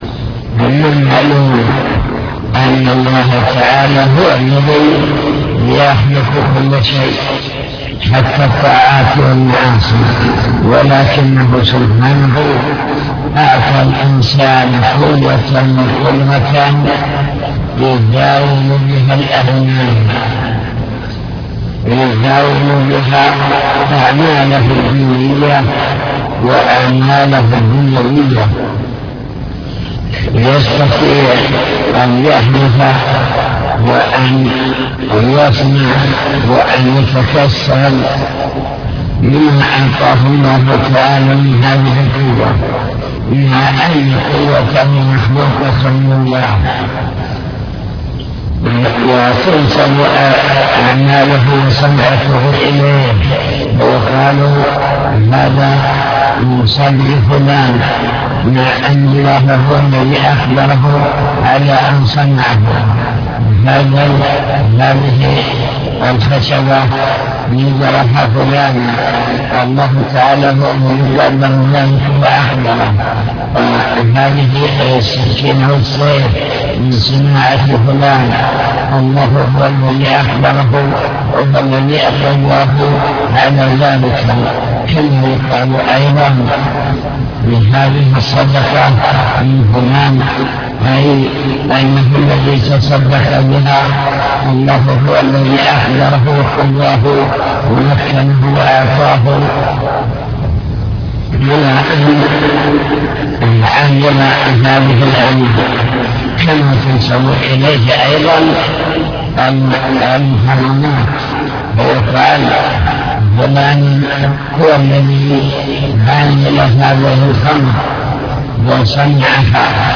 المكتبة الصوتية  تسجيلات - كتب  شرح كتاب بهجة قلوب الأبرار لابن السعدي شرح حديث كل شيء بقدر حتى العجز والكيس